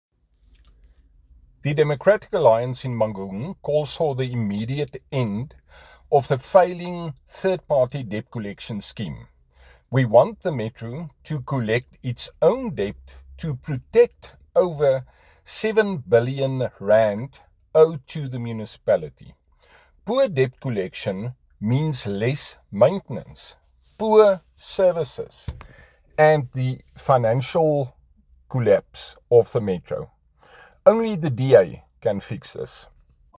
Afrikaans soundbites by Cllr Hardie Viviers and